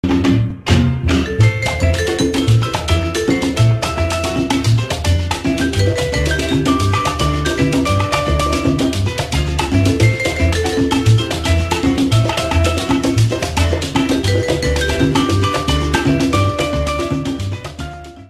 • Качество: 128, Stereo
мексиканские